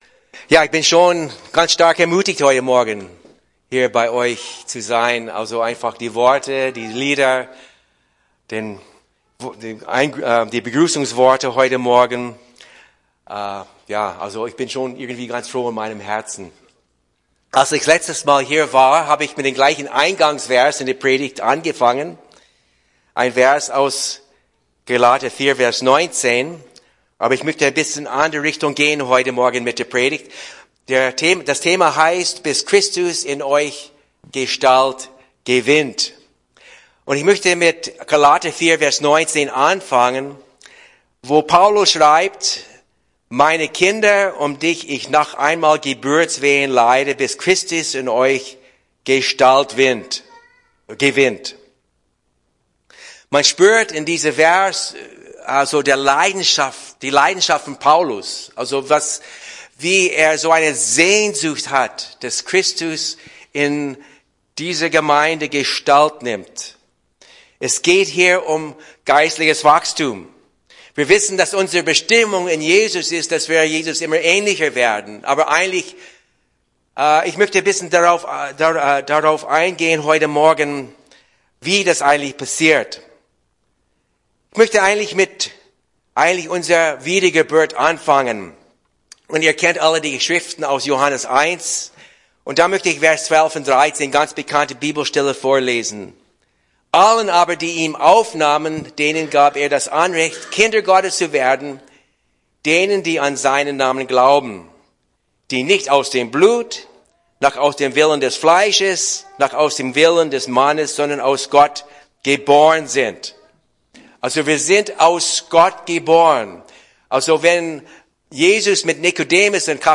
PREDIGT – FCG Sängerwald